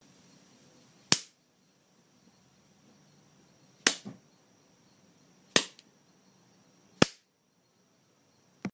Slaps
描述：a bunch of clean slap sounds.
标签： face slap clean smack foley
声道立体声